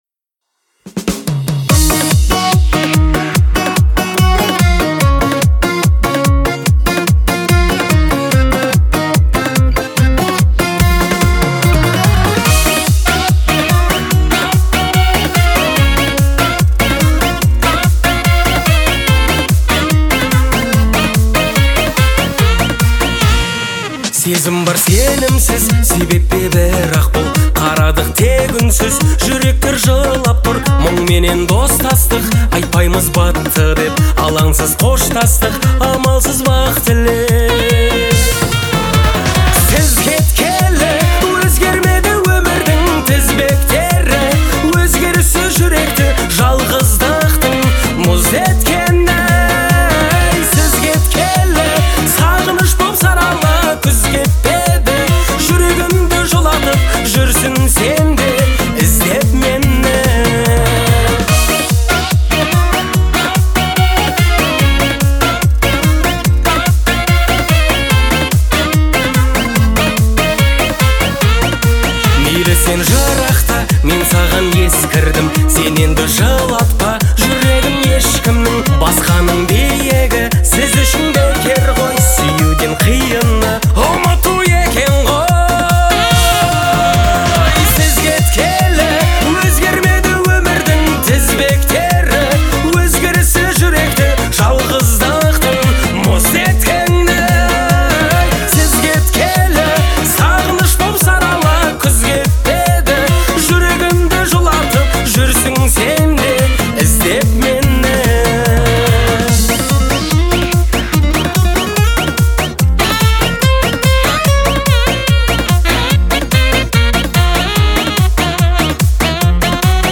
через искренний вокал и проникновенные тексты